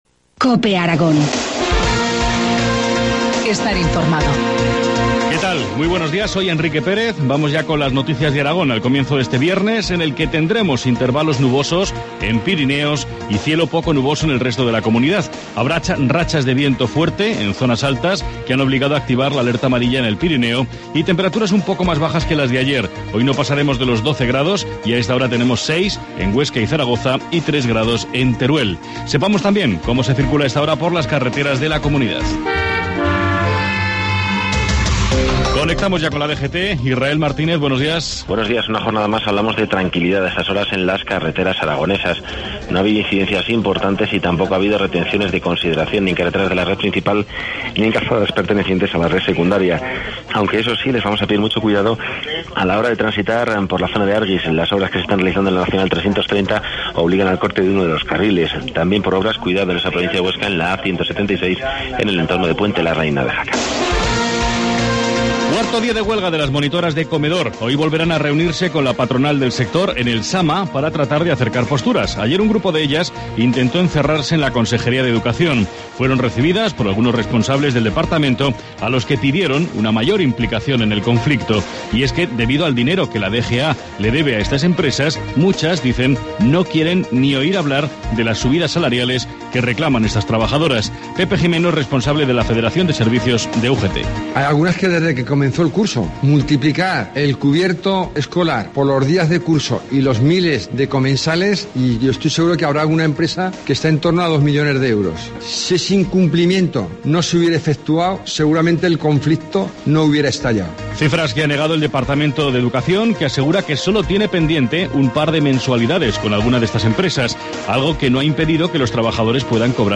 Informativo matinal, viernes 11 de enero, 7.25 horas